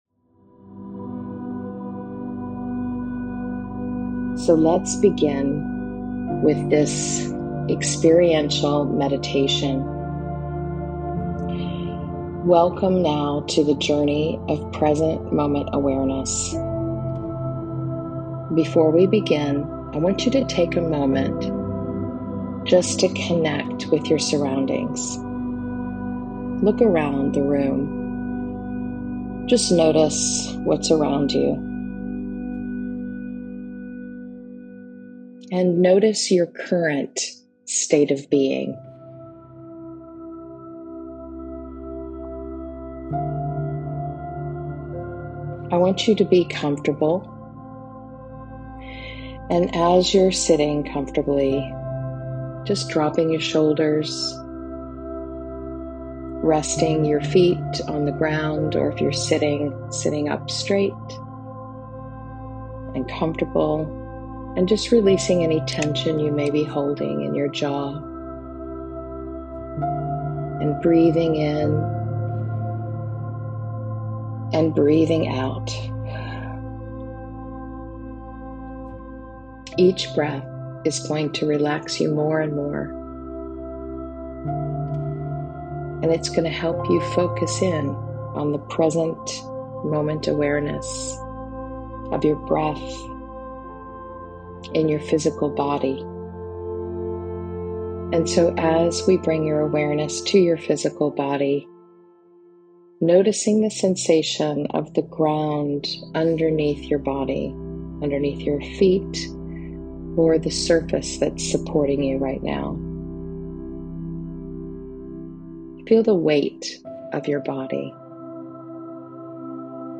This meditation invites you to fully embrace your story by grounding yourself in the present moment, where life is truly unfolding.